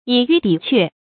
以玉抵鹊 yǐ yù dǐ què
以玉抵鹊发音